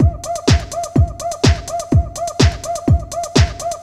Index of /musicradar/retro-house-samples/Drum Loops
Beat 12 Full (125BPM).wav